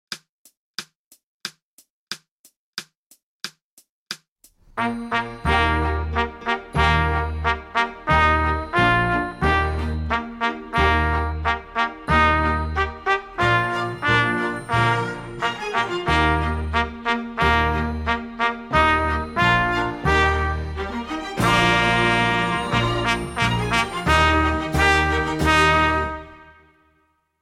Concerto